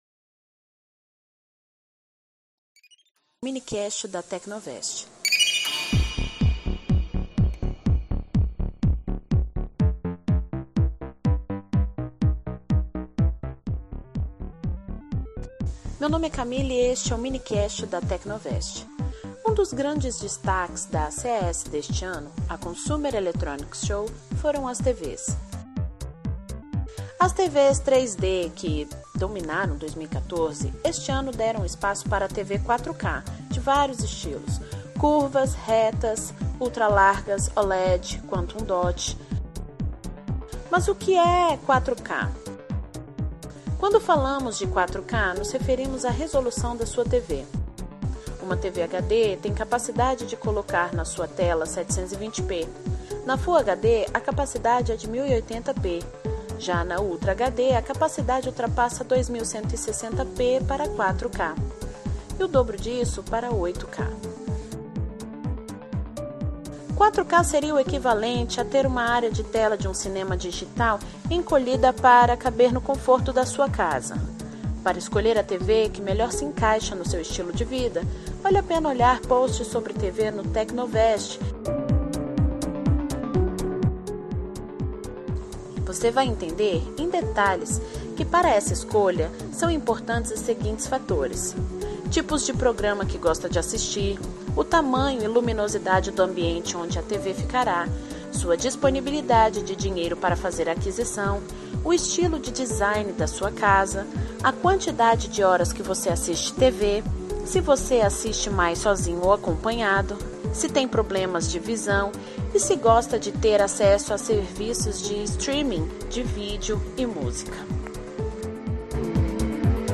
Nesse episódio, nossos criadores de conteúdo conversam sobre o advento da vacina em meio à Pandemia causada pelo Corona Vírus.